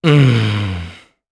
Evan-Vox-Deny_jp.wav